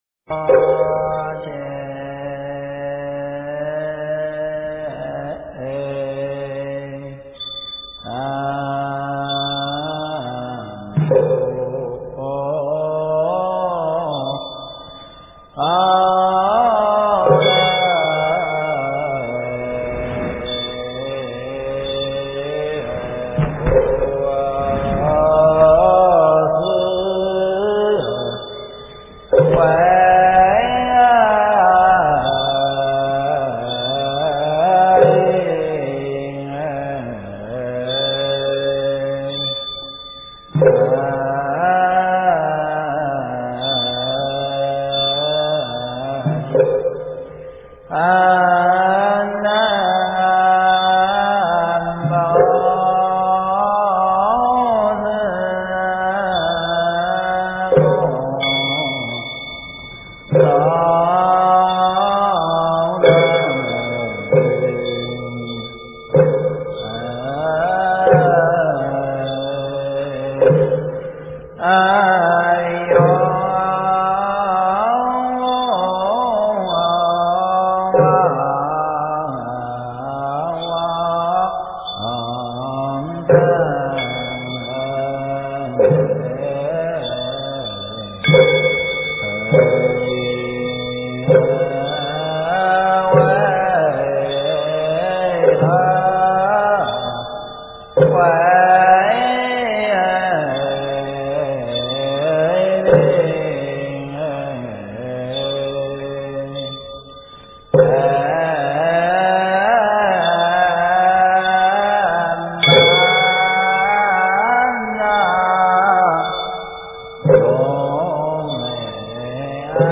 经忏
佛音 经忏 佛教音乐 返回列表 上一篇： 拜愿--群星 下一篇： 宝鼎赞--群星 相关文章 《妙法莲华经》分别功德品第十七--佚名 《妙法莲华经》分别功德品第十七--佚名...